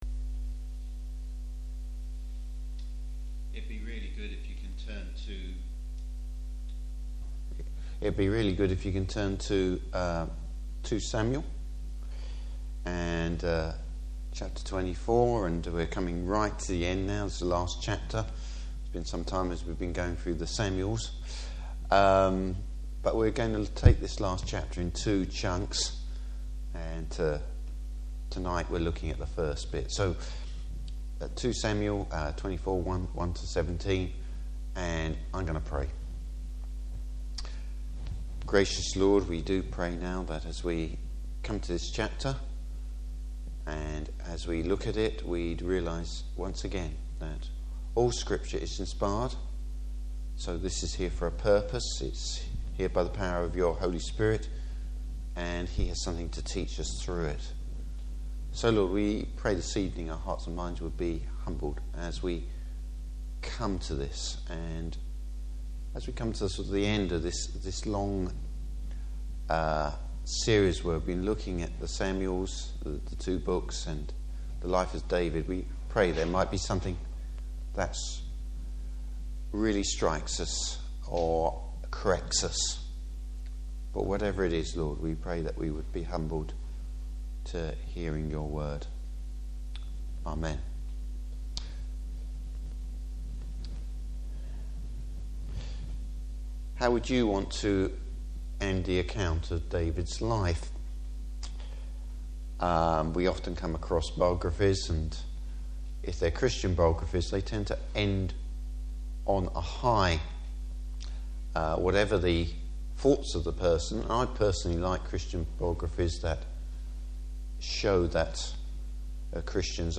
Service Type: Evening Service Does pride get the better of David?